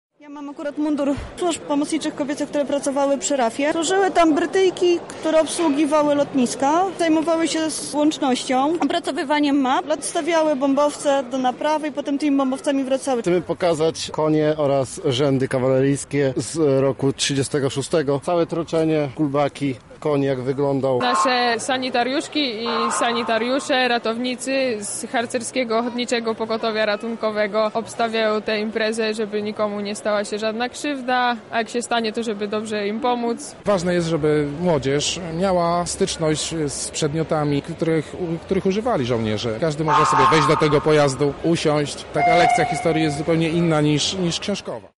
Na Targach Lublin odbył się patriotyczny piknik „Służymy Niepodległej” z okazji 100. rocznicy odzyskania niepodległości przez Polskę.
Na miejscu rozmawiała z nimi nasza reporterka: